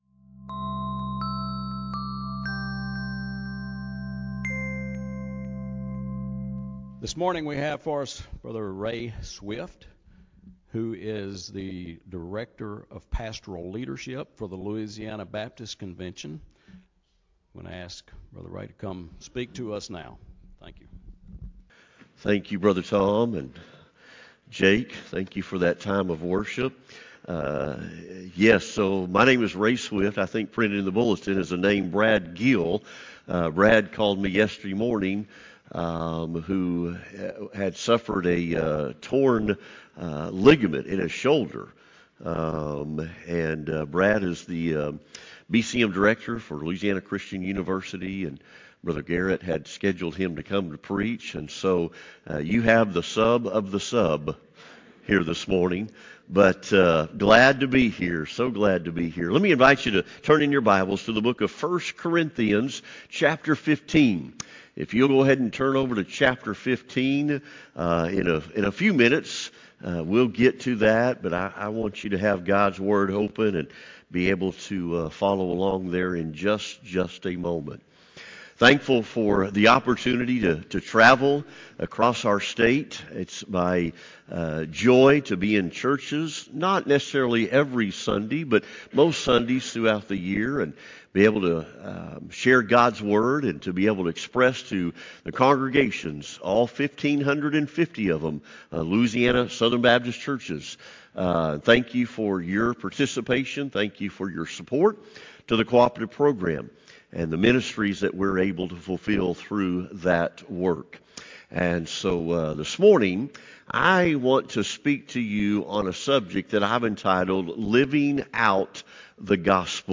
Sermon-25.1.19-CD.mp3